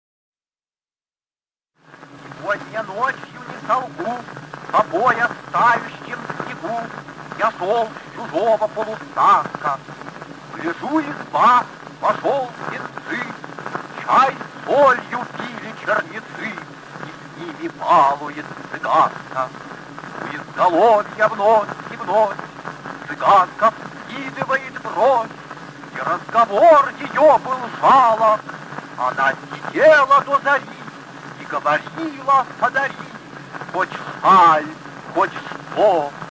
1. «Осип Мандельштам – Сегодня ночью не солгу (читает автор)» /
Mandelshtam-Segodnya-nochyu-ne-solgu-chitaet-avtor-stih-club-ru.mp3